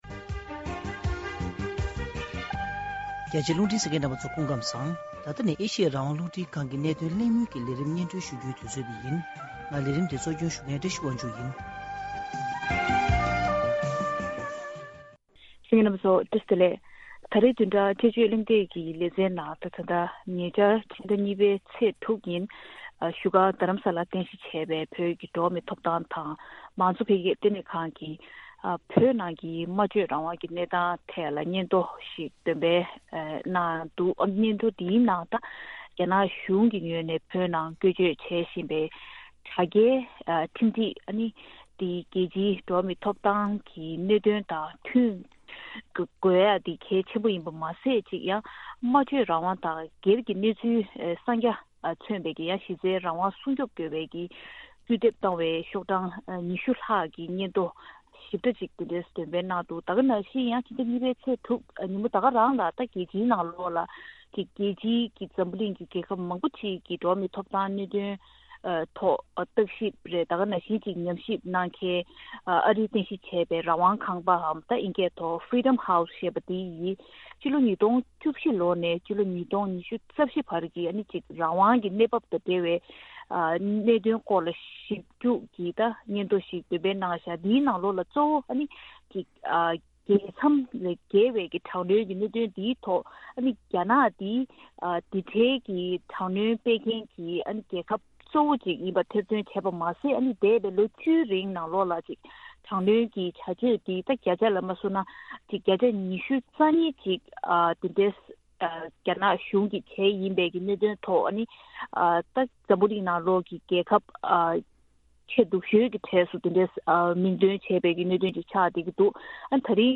བདུན་ཕྲག་འདིའི་དཔྱད་བརྗོད་གླེང་སྟེགས་ཀྱི་ལས་རིམ་ནང་གནད་དོན་འདི་དག་གི་སྐོར་གྲོས་བསྡུར་དཔྱད་གླེང་ཞུས་པར་གསན་རོགས།